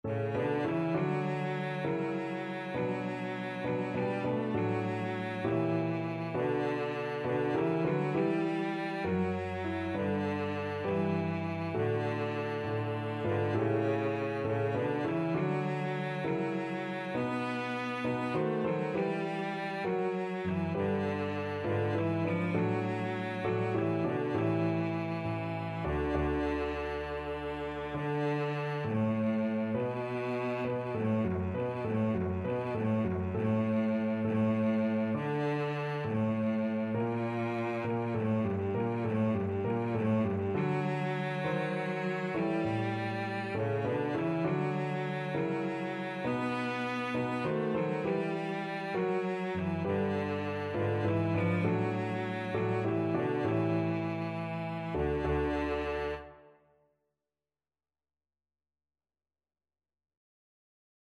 Cello version
6/8 (View more 6/8 Music)
F#3-B4
Classical (View more Classical Cello Music)